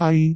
speech
syllable
pronunciation
ai3.wav